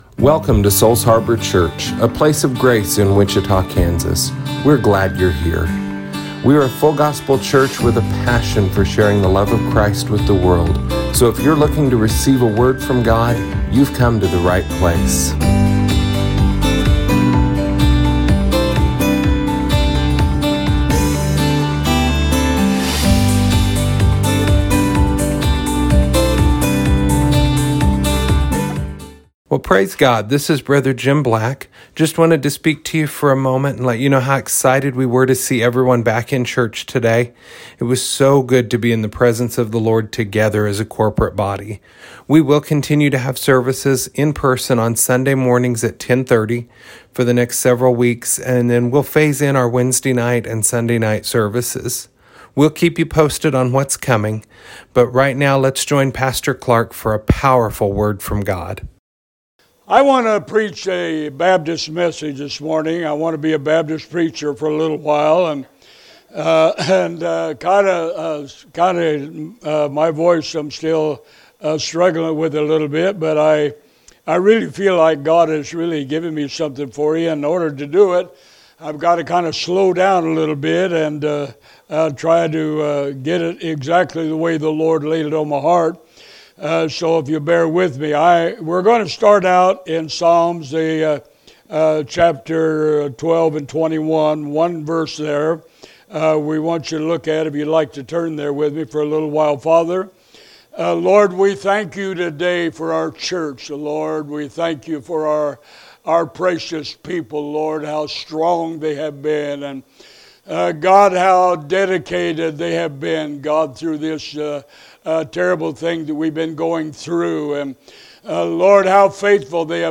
This is the audio from our first service back in-person on May 17, 2020!